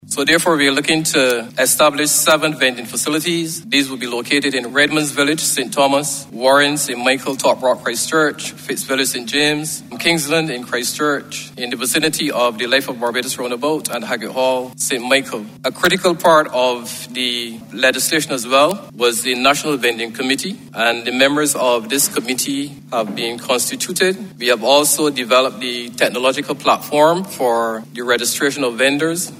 speaking during the estimates presentation in the House of Assembly.